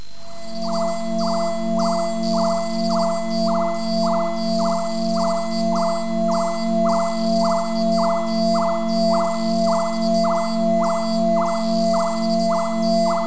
e_swamp.mp3